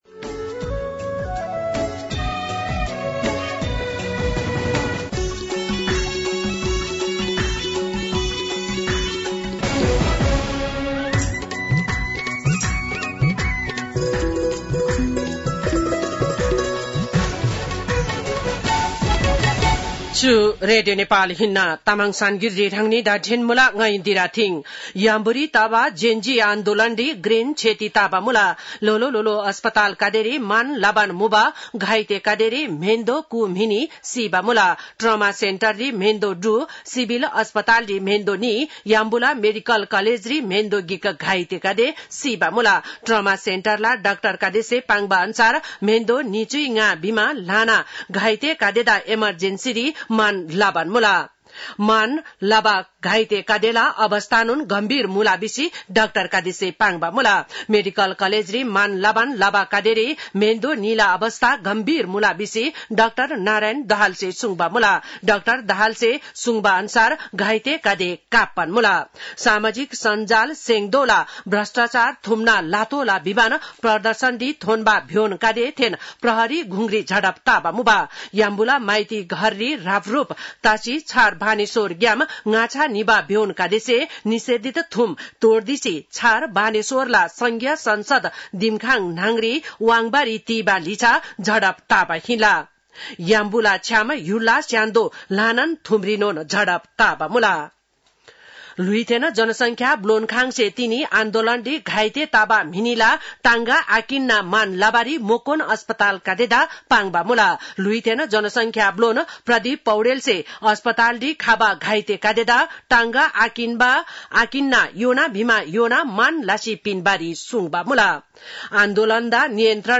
तामाङ भाषाको समाचार : २३ भदौ , २०८२
Tamang-News-23.mp3